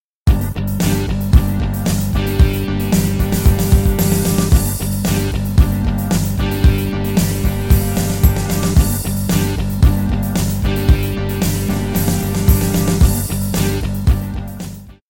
Daher erklingt von der Begleitsektion immer unverändert das selbe Rock Pattern.
Beispiel 6 (drei verschiedene Fill-Ins, die Main Variation bleibt gleich)
3Fills.mp3